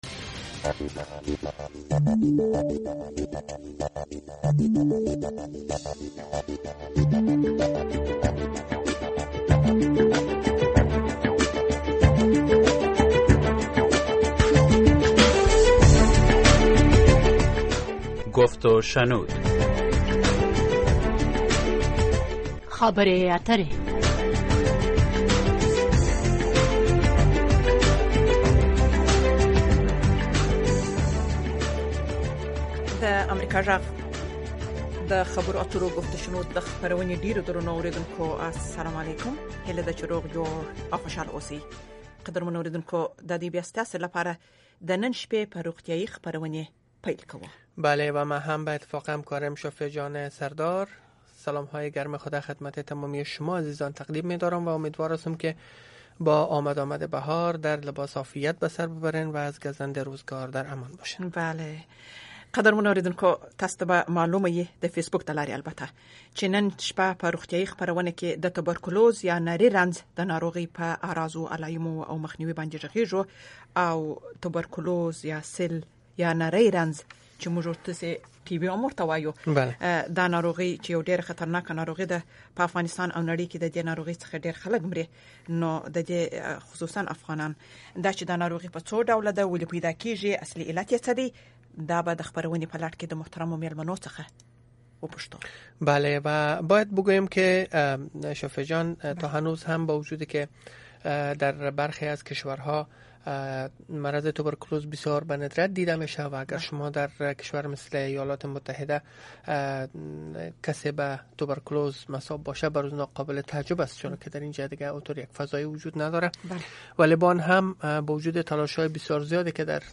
Dari and pashto Health Call-In Show